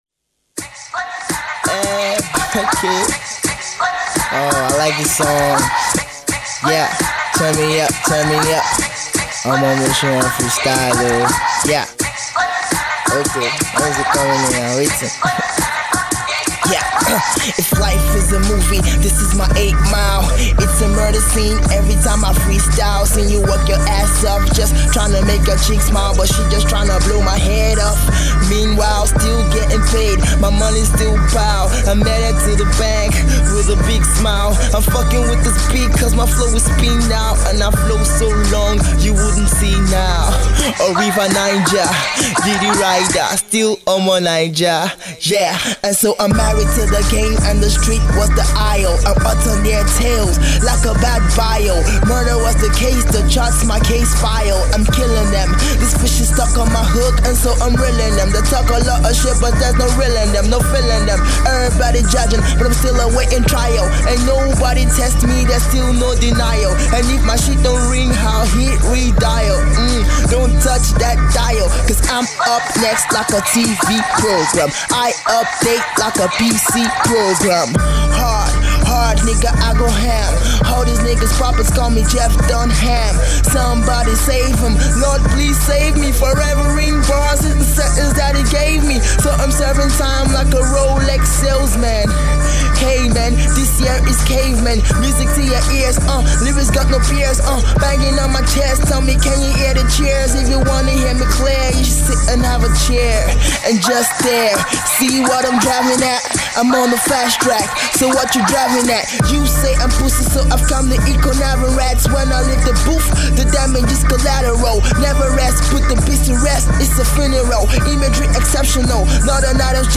Rising MC